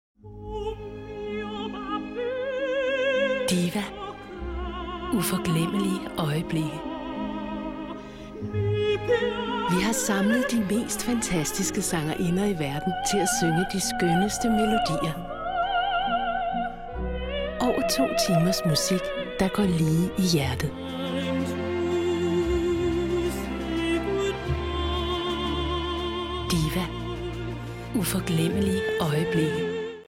Experienced female danish voiceover with warm, thrustworthy voice with a natural smile in it
Sprechprobe: Werbung (Muttersprache):
I specialize in accent-free Danish voiceover for all media and have my own professional recordingstudio.